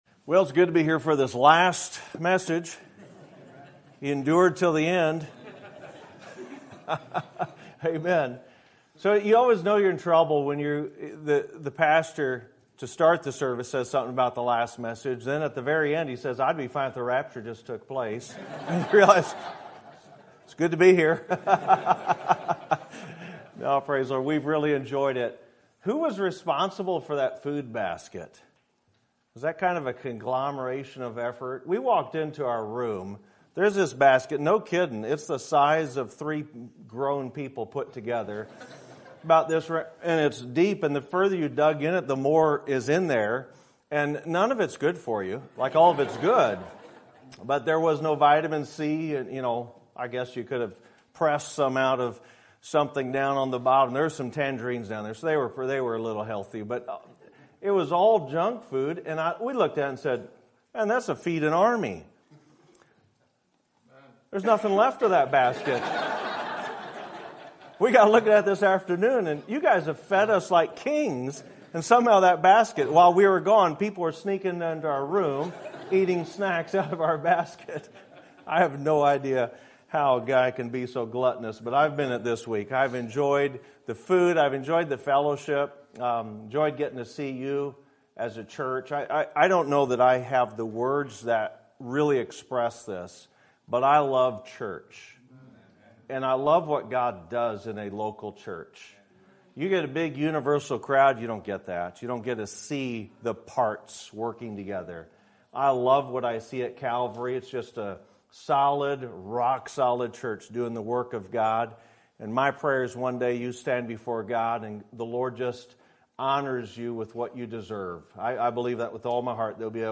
Sermon Date
Sermon Topic: Winter Revival Sermon Type: Special Sermon Audio: Sermon download: Download (22.83 MB) Sermon Tags: 2 Peter Faith Peter Goal